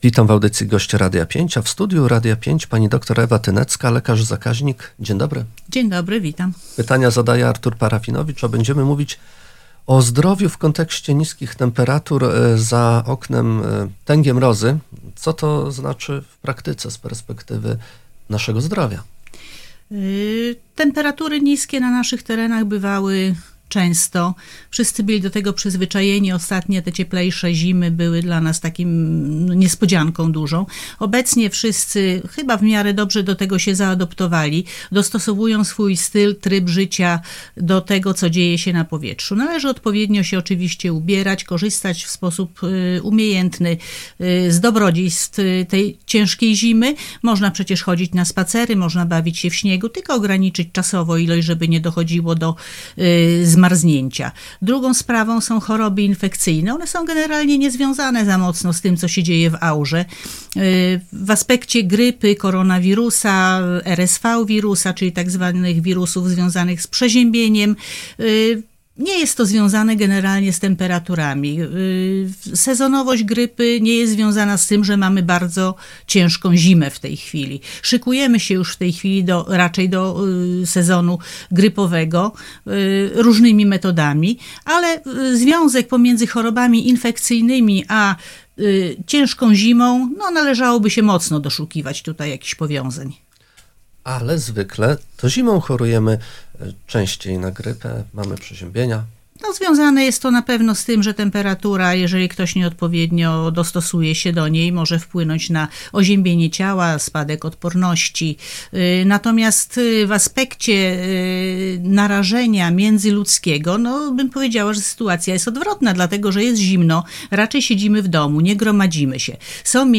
Cała rozmowa: https